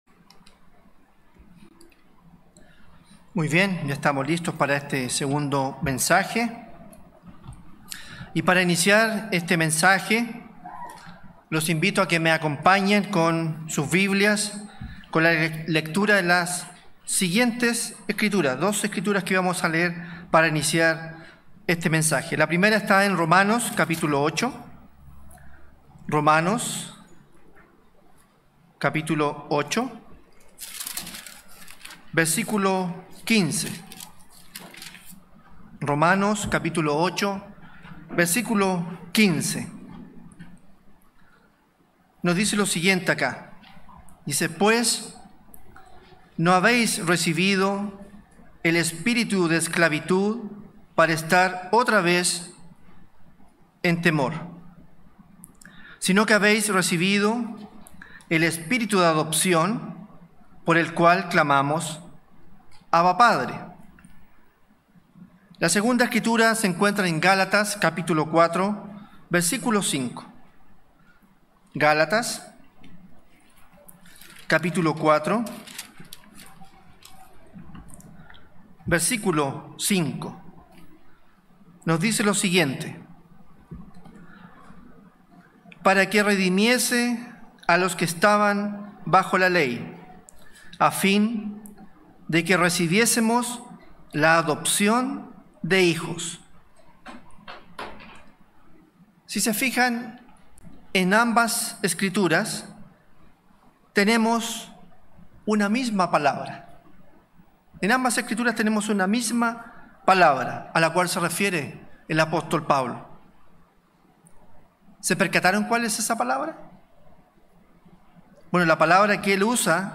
Given in Santiago